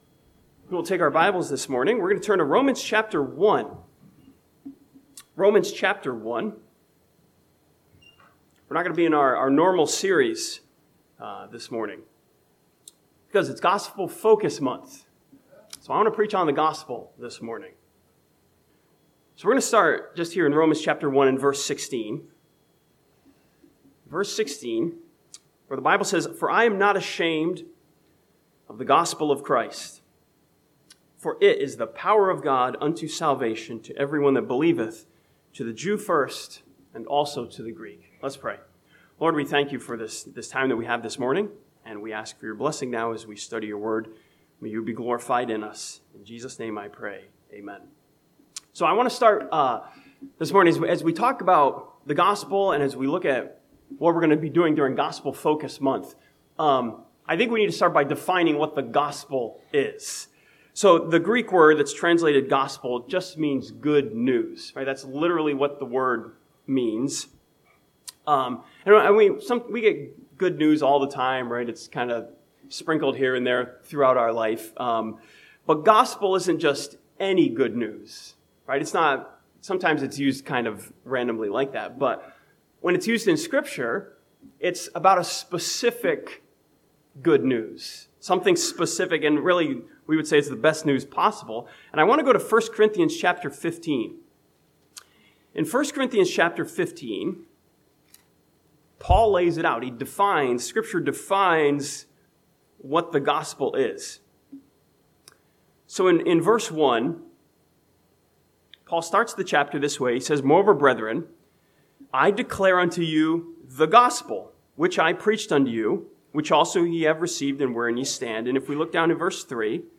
This sermon from Romans chapter 1 starts Gospel Focus Month with a message challenging us with our responsibility to preach the Gospel.